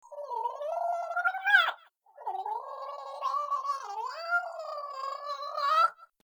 In dieser Zeit erfüllen sie die Wälder mit ihren durchdringenden, gurgelnd schrillen
Rufen, die ein Männchen herbeirufen sollen.